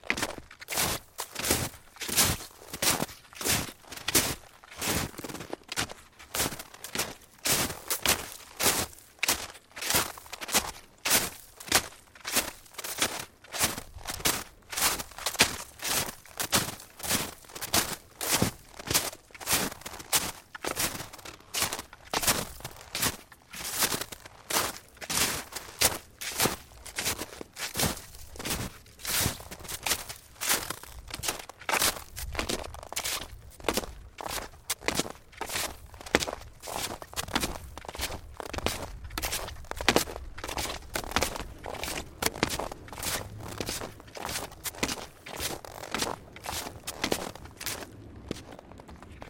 冬天 " 脚步声 雪鞋 老木头2 填充雪道 中等速度 脆皮
描述：脚步雪鞋老wood2包装雪径中速crunchy.flac
Tag: 包装 脚步声 雪鞋 越野 雪地 wood2